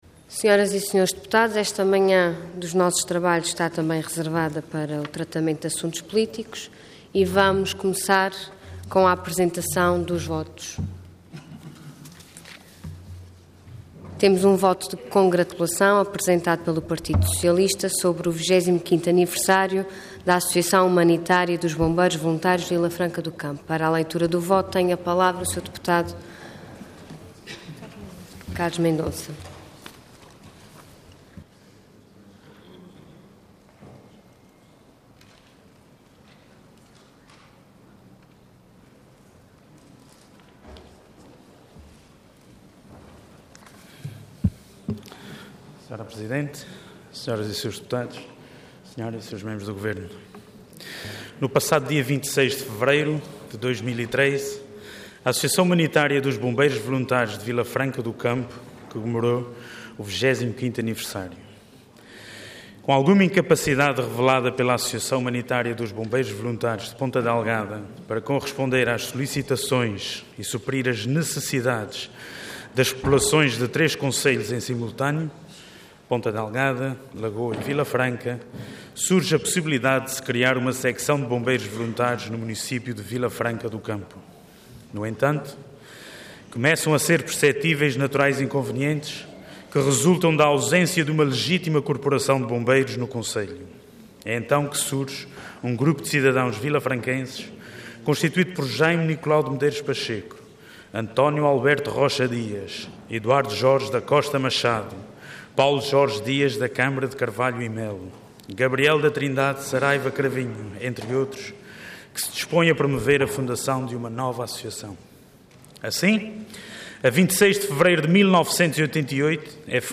Intervenção Voto de Congratulação Orador Carlos Mendonça Cargo Deputado Entidade PS